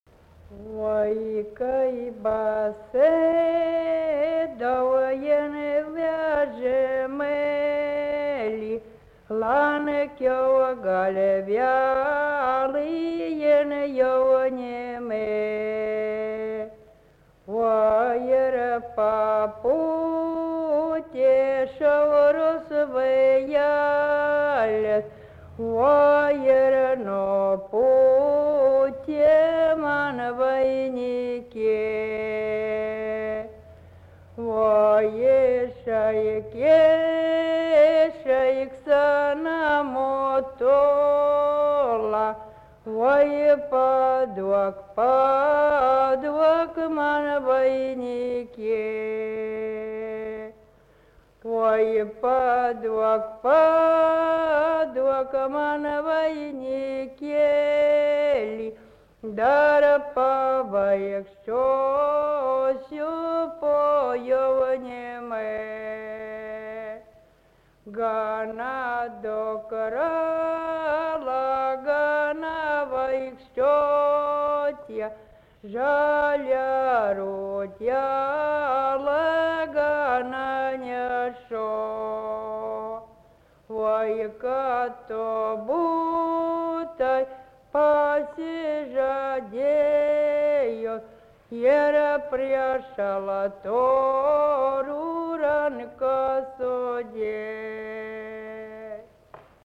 Subject daina
Erdvinė aprėptis Viečiūnai
Atlikimo pubūdis vokalinis
Su komentaru (kada dainuodavo šitą dainą)